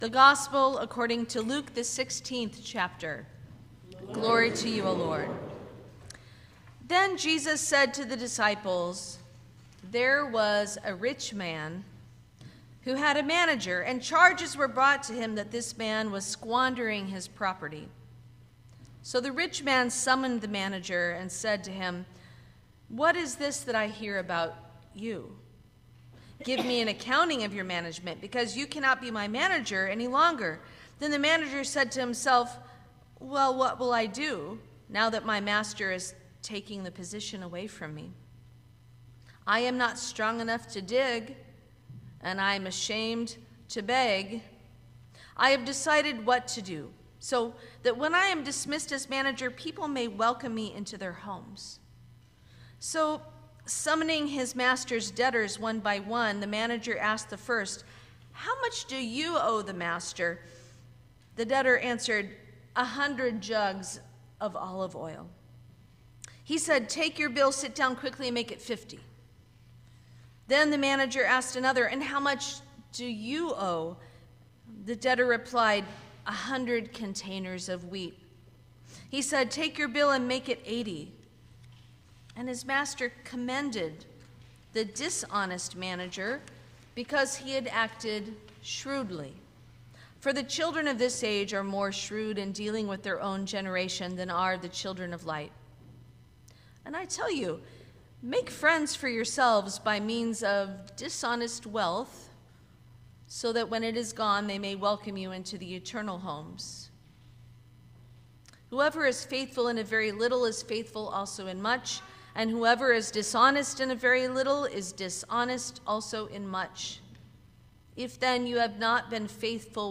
Sermon for the Fifteenth Sunday after Pentecost 2025